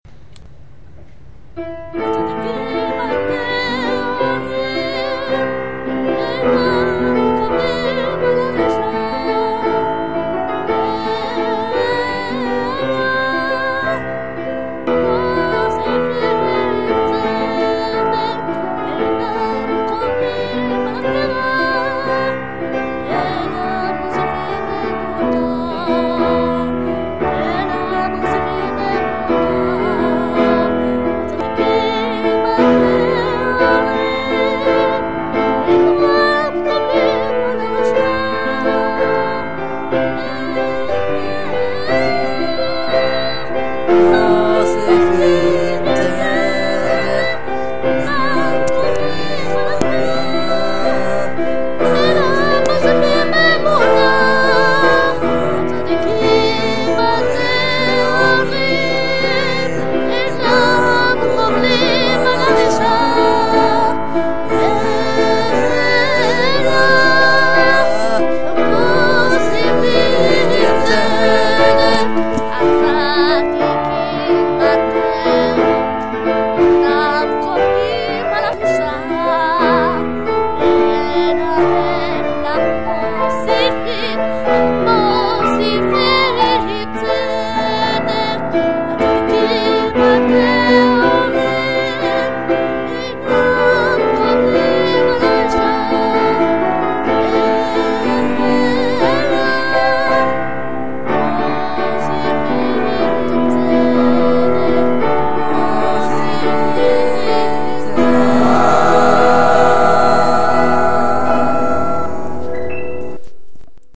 לחן ונגינה: אני
שירה: אח שלי
קולות(באמצע ובסוף): אני
מצטער על הרעשים באמצע
הקול ממש צורם לי..זה בכוונה בטון כזה גבוה?
קצת צורם הקול הגבוה..גם ההקלטה לא משו..